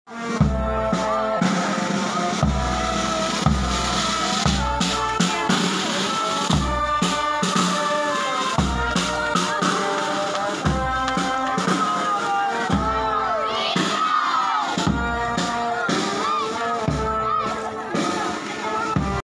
alas Mp3 Sound Effect October 7, 2024 (Lunes) alas 4:00 nin hapon nangyari Traslacion and Fluvial Procession ni Virgen de Pen̈afrancia asin Divino Rostro sa Barangay Tagas, San Jose.